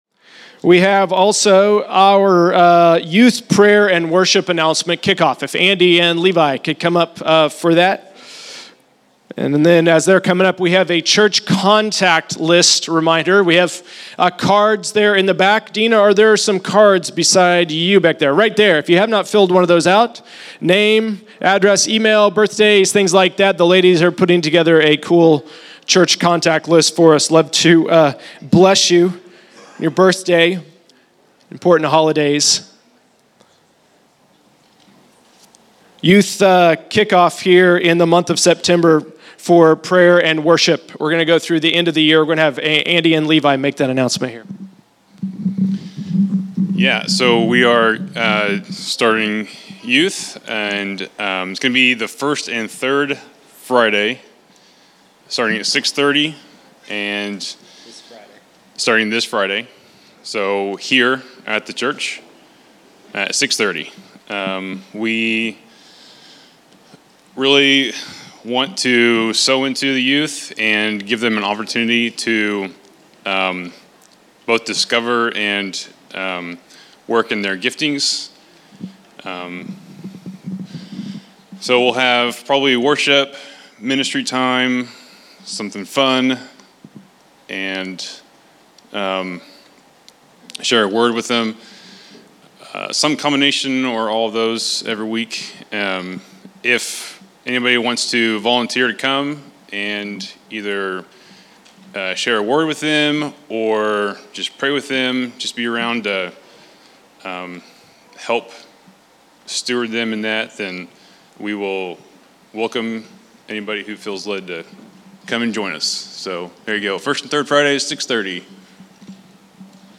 August 28, 2022      |     By: El Dorado Equip      |      Category: Announcements      |      Location: El Dorado